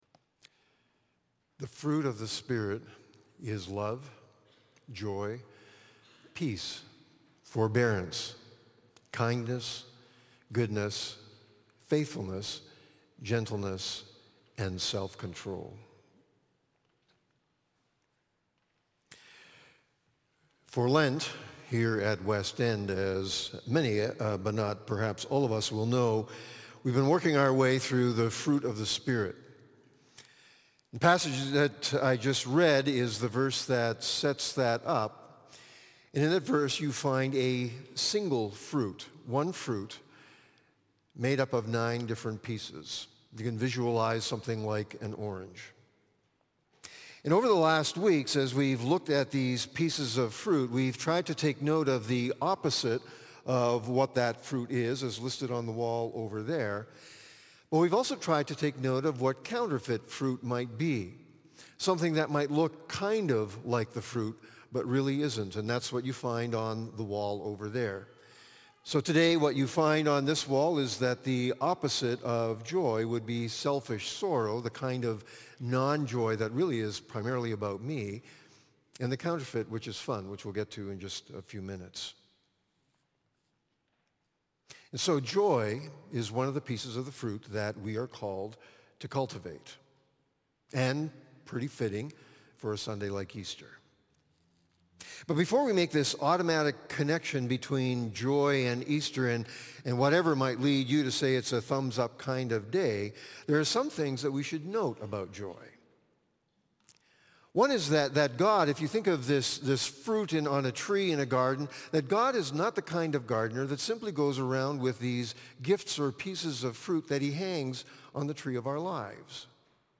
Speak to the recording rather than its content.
Service Type: Easter Sunday